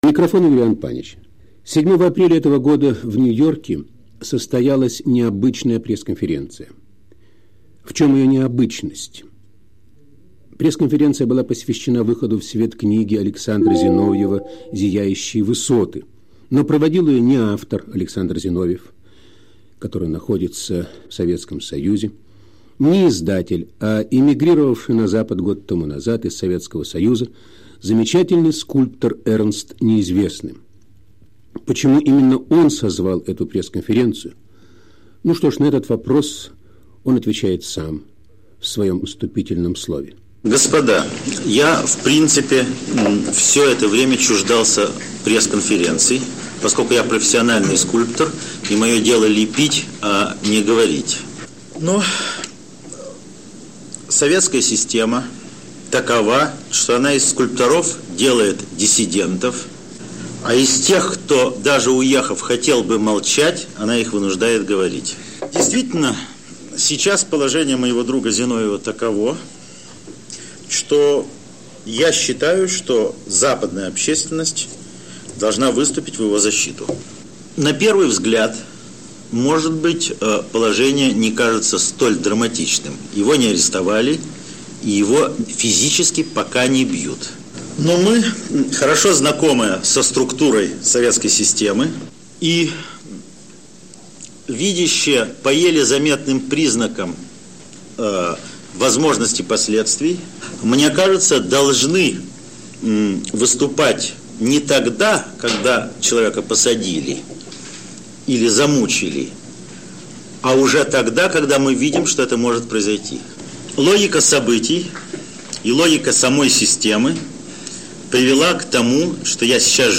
7 апреля 1977 года, пресс-конференция Эрнста Неизвестного, посвященная выходу книги Александра Зиновьева "Зияющие высоты"